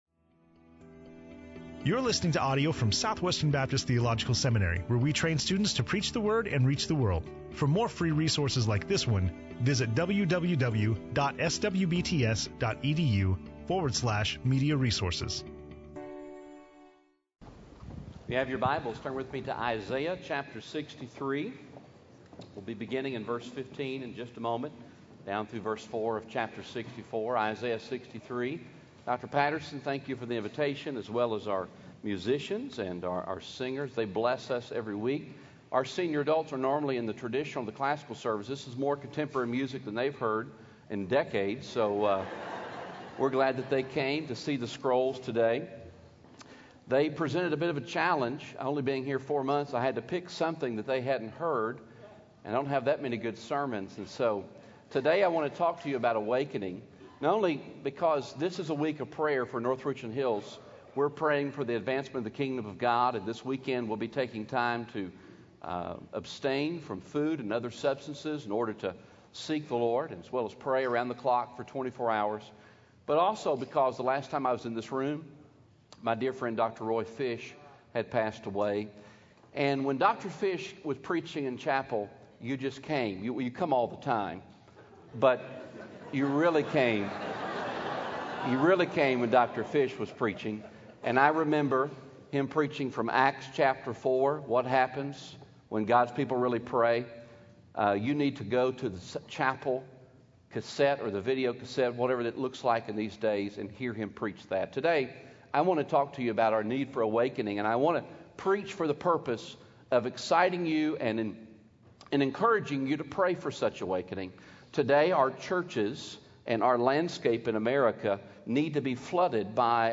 speaking on Isaiah 63:15-64:4 in SWBTS Chapel on Thursday November 8, 2012